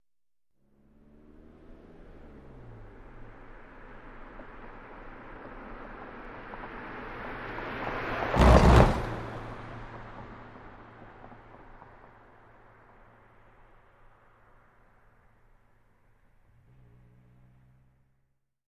Car Tires Over Cattle Guard 2x